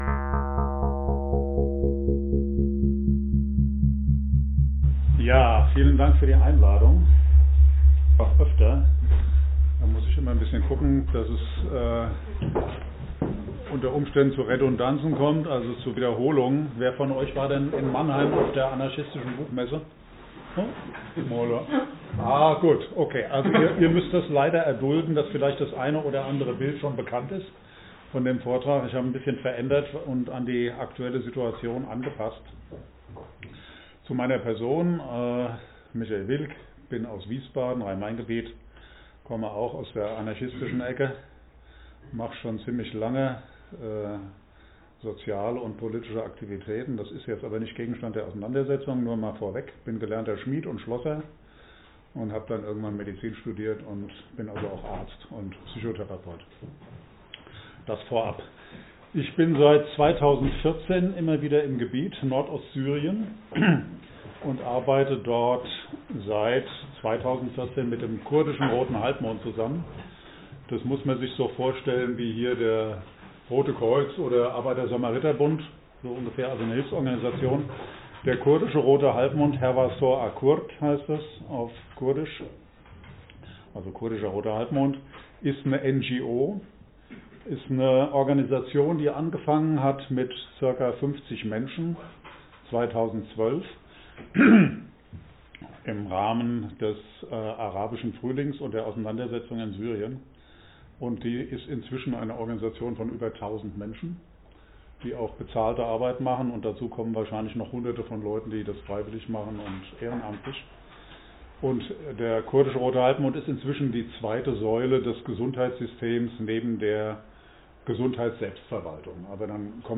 1 Doku: Vortrag – Die Situation in Nord-Ostsyrien vom 21.02.2025 1:33:46 Play Pause 21h ago 1:33:46 Play Pause Später Spielen Später Spielen Listen Gefällt mir Geliked 1:33:46 Das Gesellschaftsmodell Nord-Ostsyriens ‚Rojava‘ und die damit verbundene Organisierung basisdemokratischer Selbstverwaltung ist eine Herausforderung, die großen Einsatz auf Seiten der Menschen Rojavas erfordert.